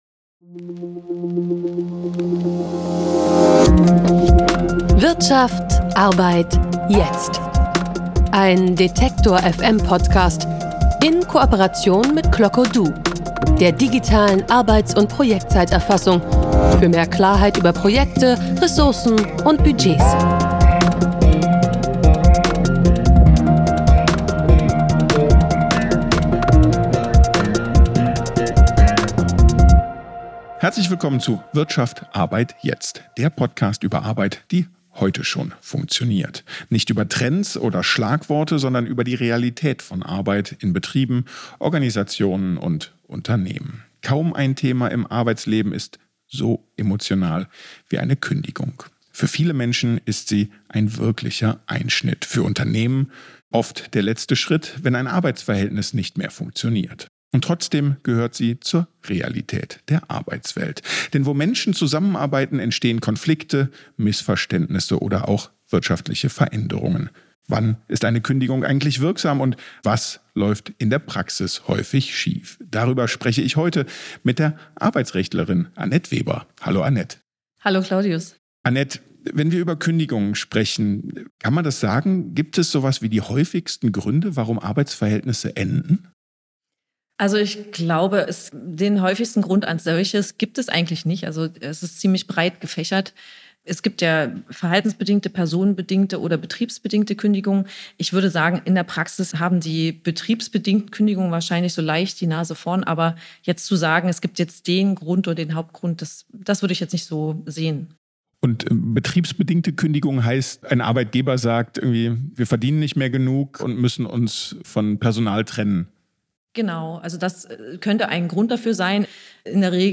Podcast-Radio